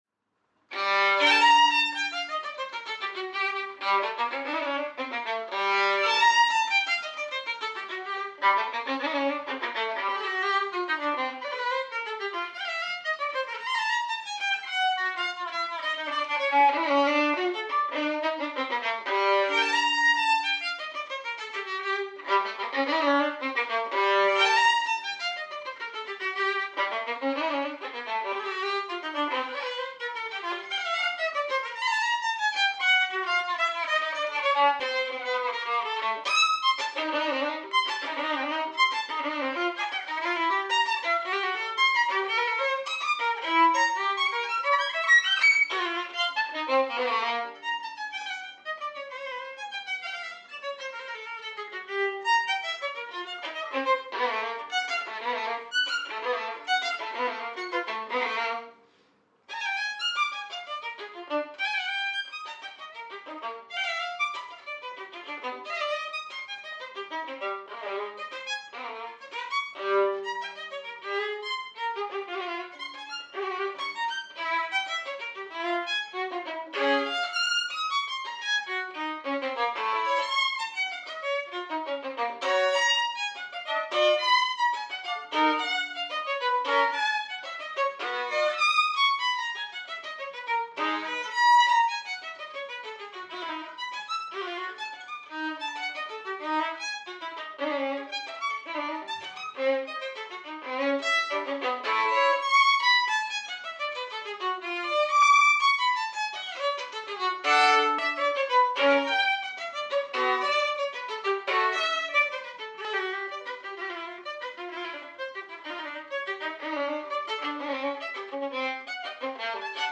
Workshop Recording- 21st May 2012 Wapping
At the practice desk-Paganini Caprice No 10  and 22 with the Steel bow, held mid way along the tinselling